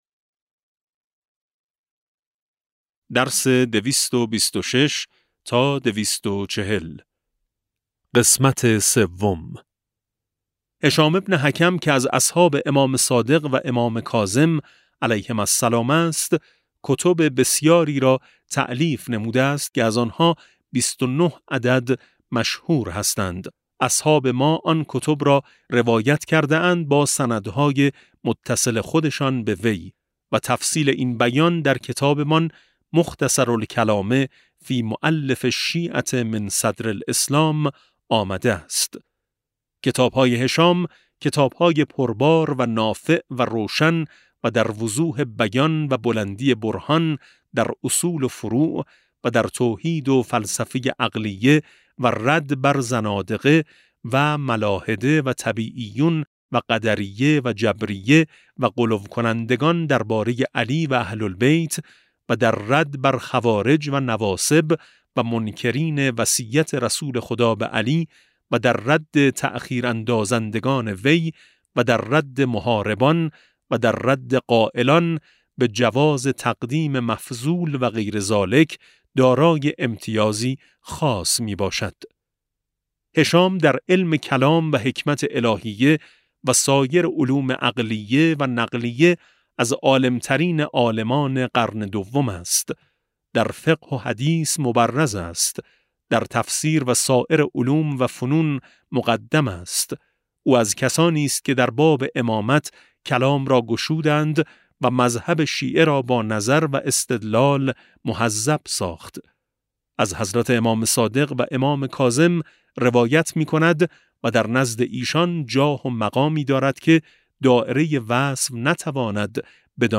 کتاب صوتی امام شناسی ج 16 و17 - جلسه3